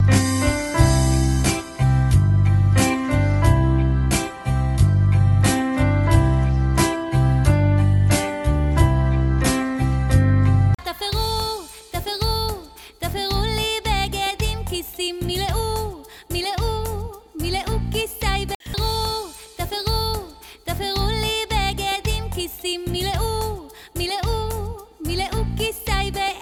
תפרו-פסנתר-ושירה-1.mp3